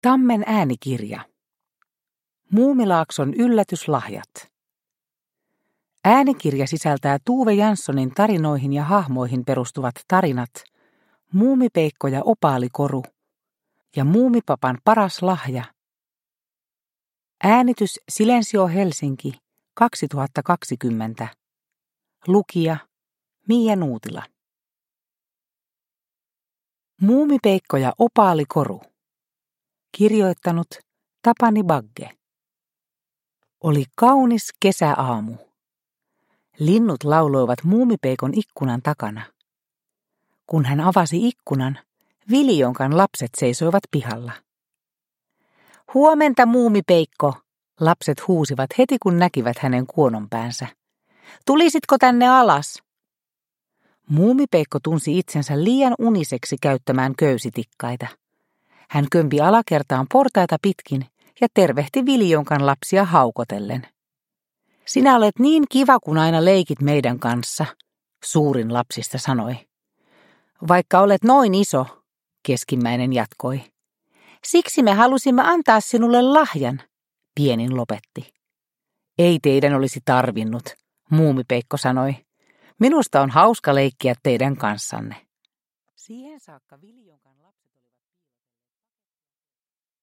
Muumilaakson yllätyslahjat – Ljudbok – Laddas ner